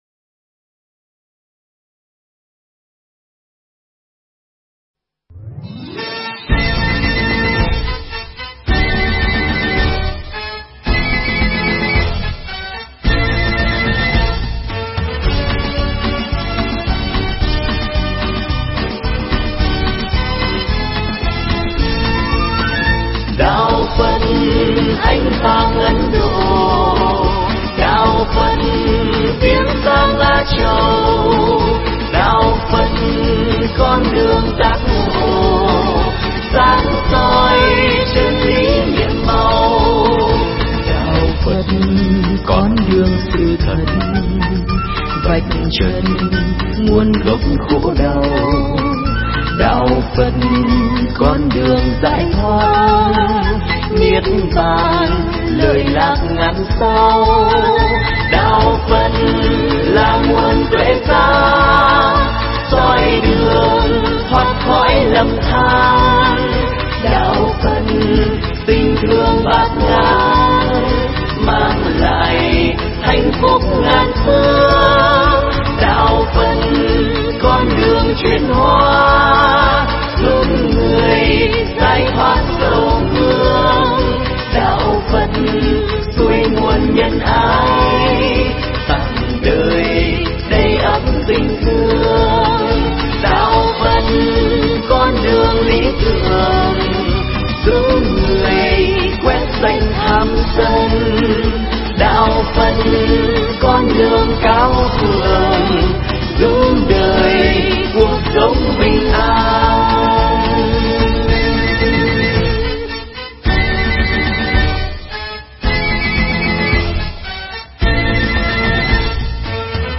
Nghe mp3 thuyết pháp Quay đầu là bờ – thầy Thích Nhật Từ giảng tại Trại Giam K20 – Huyện Giồng Trôm – Tỉnh Bến Tre, ngày 05 tháng 02 năm 2007.